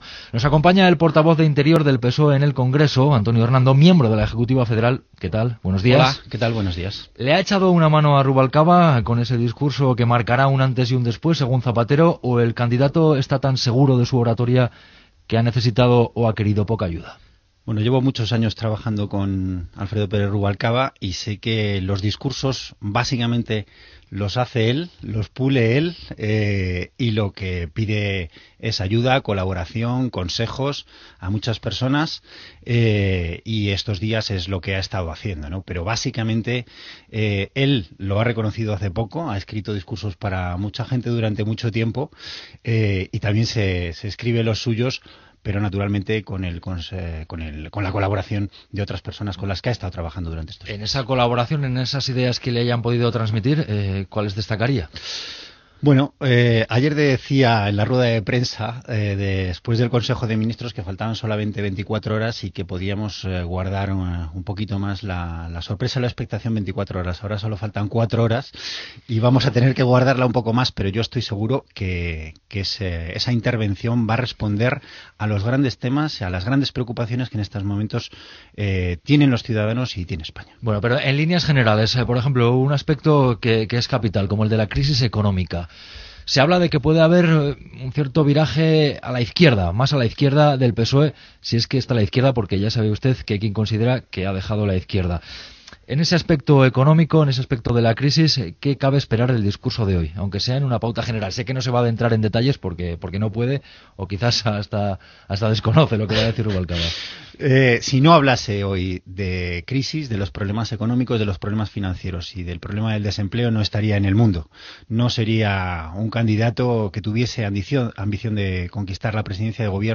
Entrevista
Gènere radiofònic Informatiu